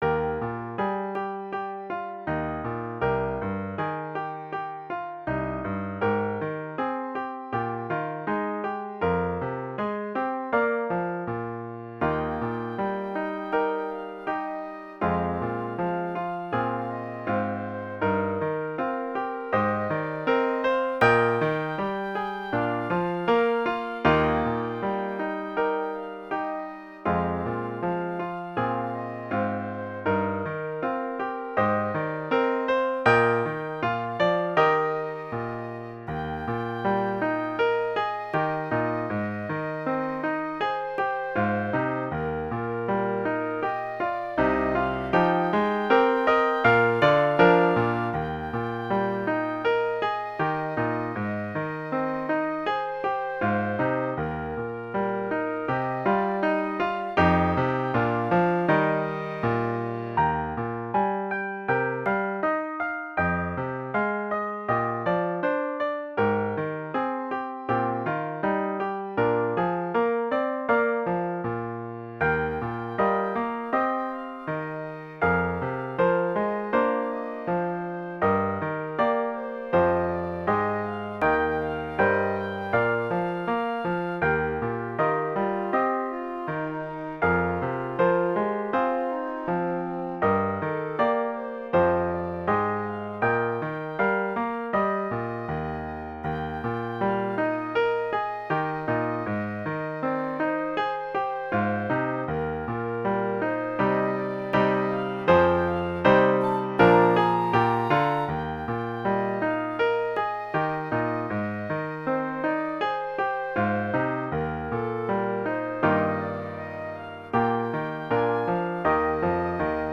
Intermediate Instrumental Solo with Piano Accompaniment.
Christian, Gospel, Sacred.
puts the sacred theme to a gentle, meditative mood.